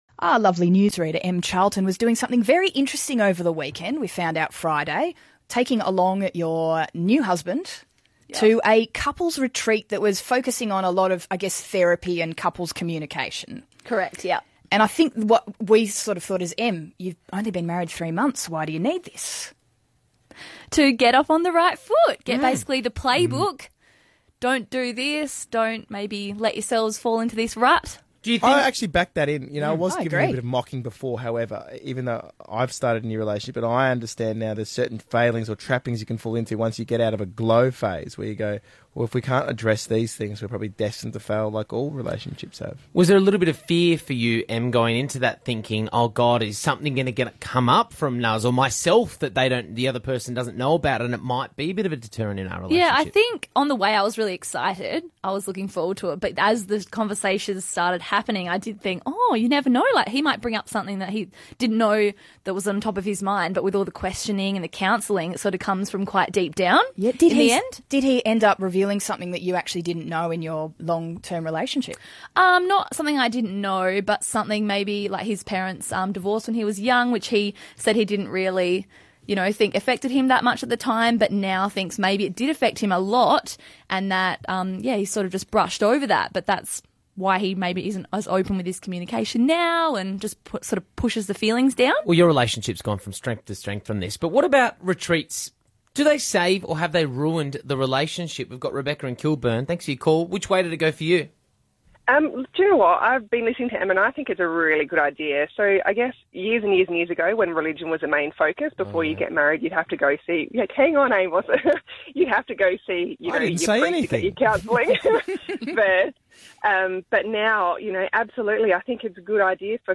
Radio interview
Hit107_Radio_Interview.mp3